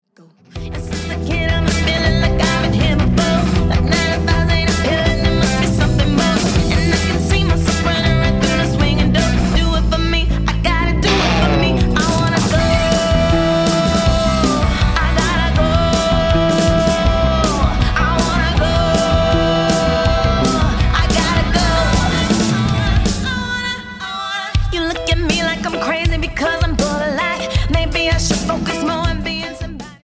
BPM 160